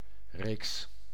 Ääntäminen
IPA: /ˈfœljd/